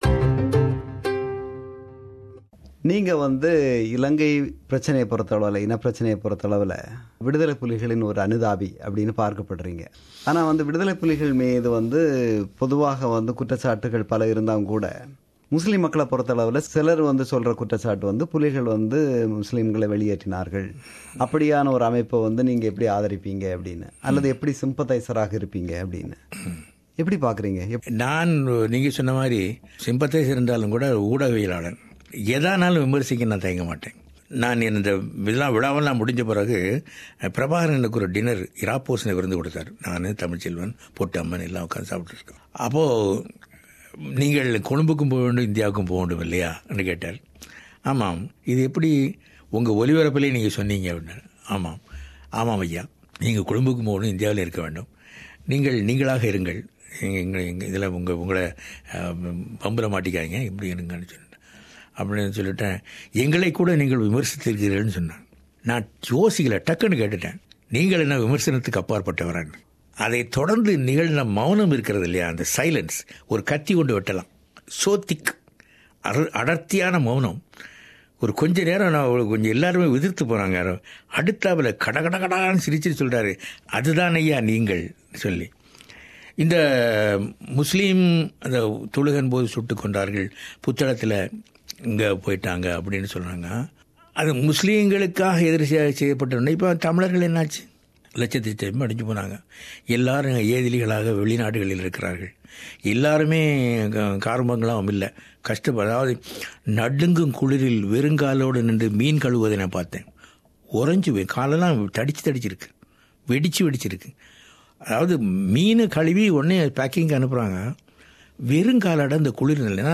கடந்த 2016ம் ஆண்டு ஆஸ்திரேலியா வருகை தந்திருந்த அவர் நமக்கு வழங்கிய சிறப்பு நேர்முகத்தின் நிறைவுப் பகுதி.